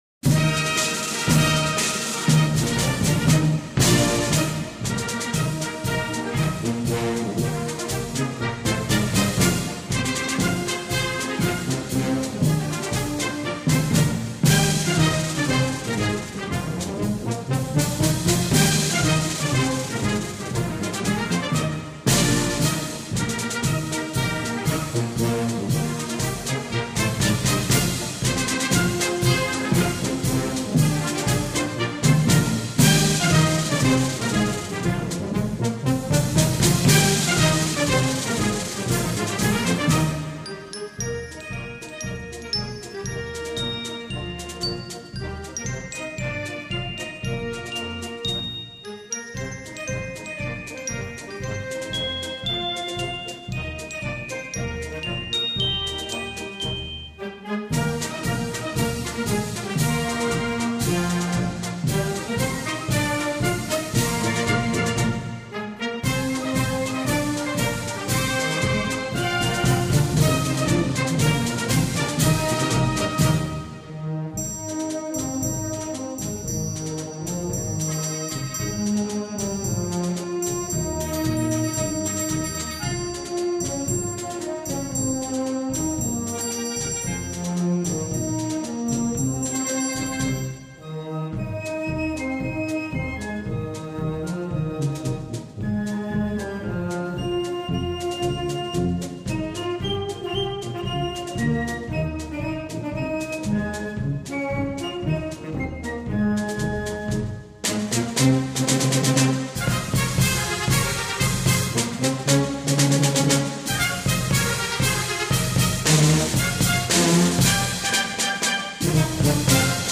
每当我们在大型典礼，迎宾送宾，开闭幕式，体育盛会，入场升旗等礼仪活动中，听到雄壮的军礼乐演奏，心中就会掀起激动的浪潮。